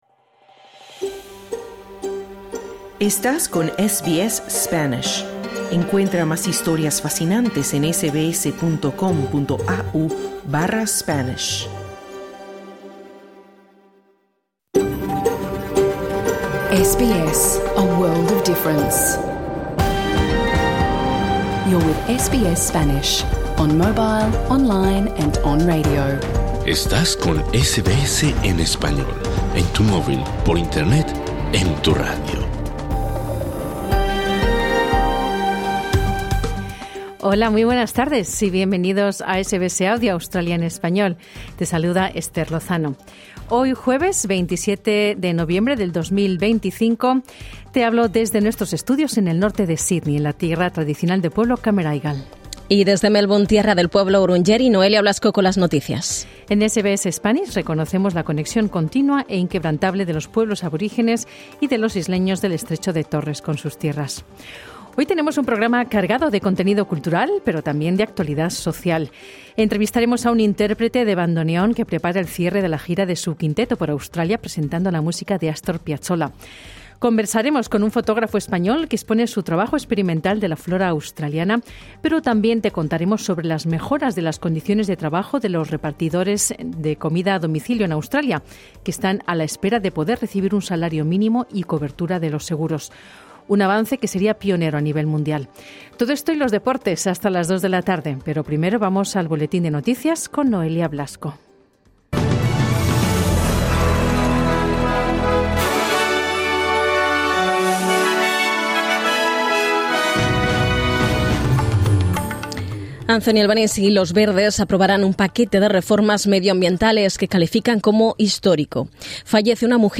Programa en Vivo | SBS Spanish | 27 de noviembre 2025 Credit: Getty Images